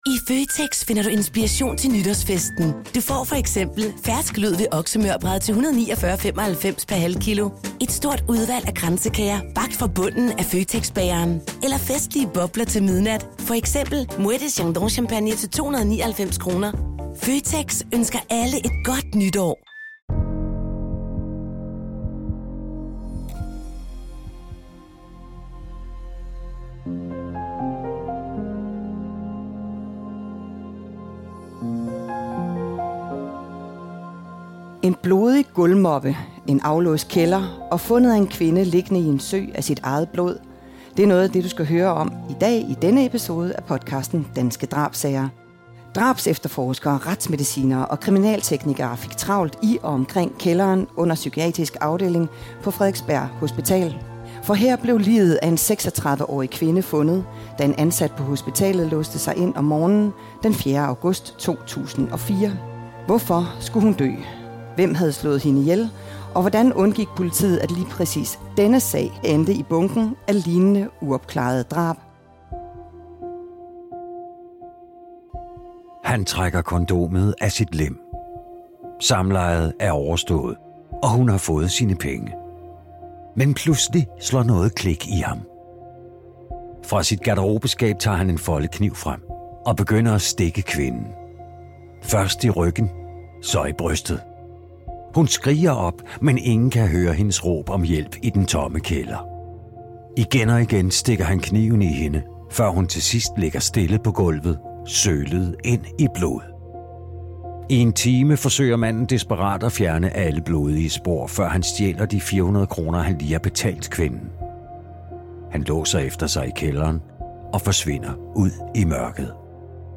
Episoden er optaget på Rigspolitiets scene ved Folkemødet 2025.